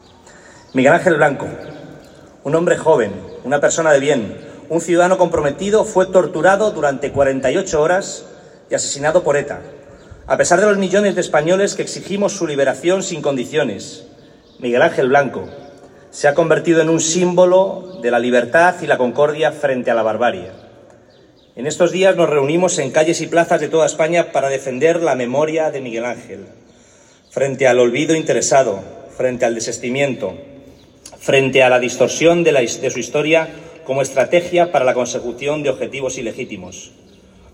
Asimismo, Velázquez ha participado en la lectura de un manifiesto, acordado por unanimidad de todos los grupos con representación municipal, que recoge la voluntad de defender la memoria de Miguel Ángel Blanco, frente al olvido.
Cortes de voz
carlos-velazquez-manifiesto-3.m4a